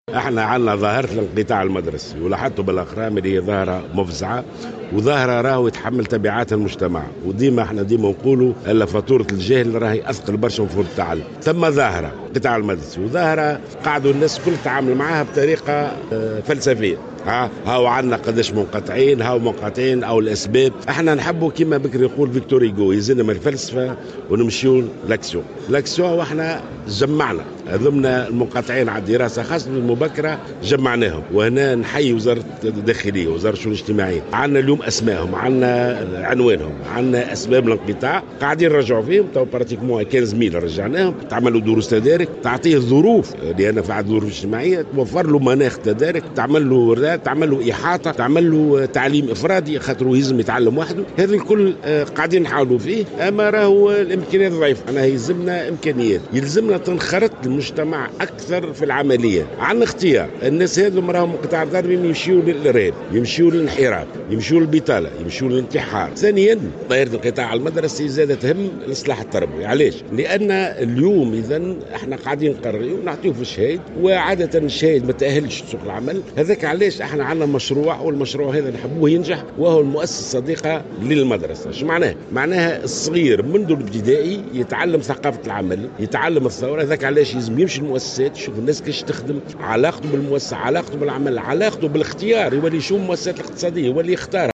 أعلن وزير التربية ناجي جلول في تصريح